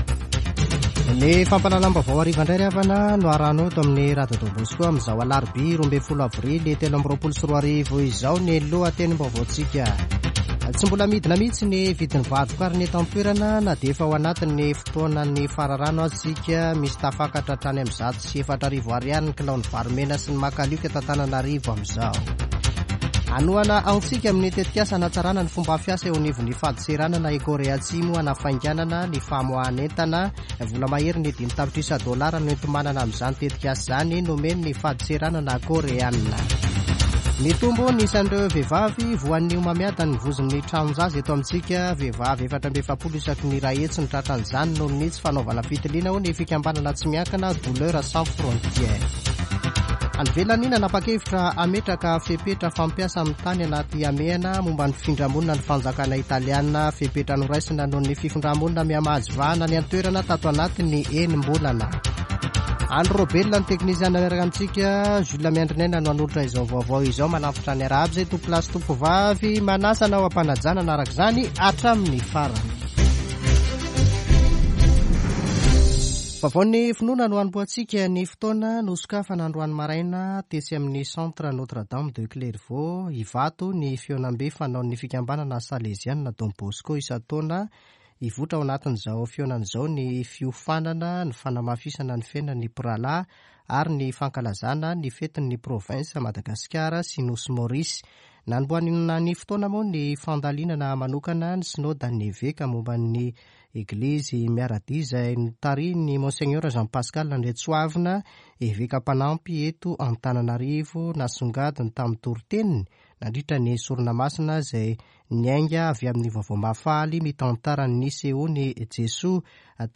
[Vaovao hariva] Alarobia 12 avrily 2023